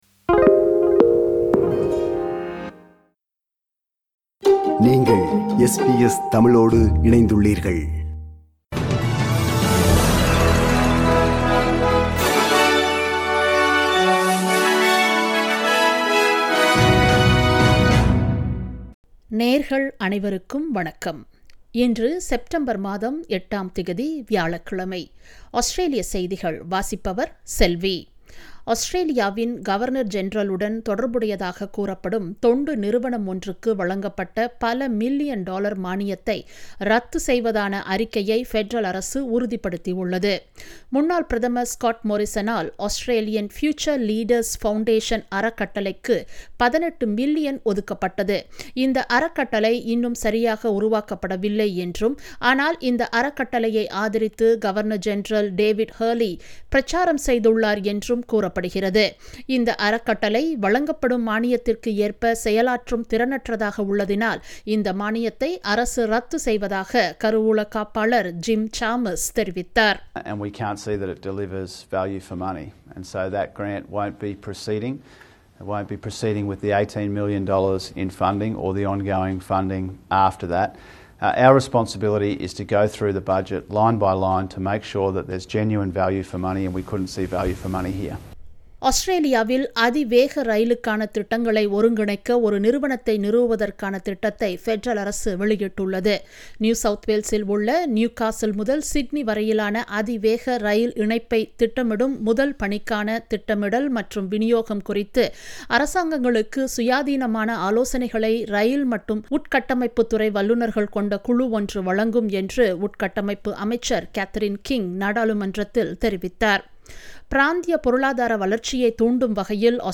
Australian news bulletin for Thursday 08 September 2022.